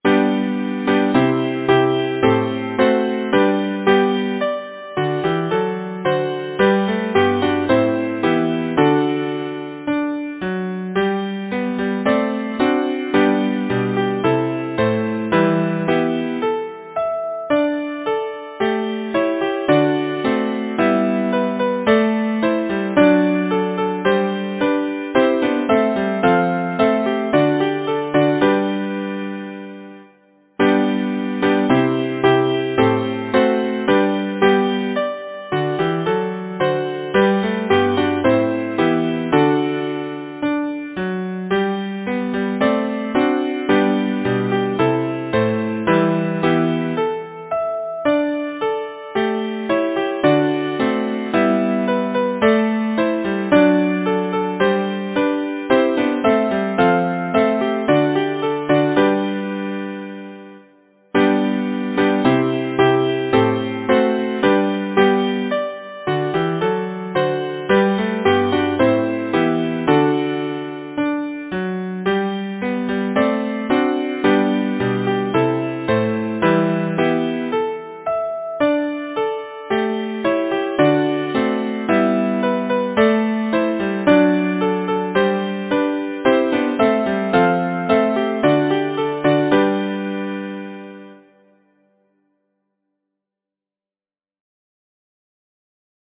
Title: The faithful shepherd Composer: Ernest Harry Smith Lyricist: May Byron Number of voices: 4vv Voicing: SATB Genre: Secular, Partsong, Madrigal
Language: English Instruments: A cappella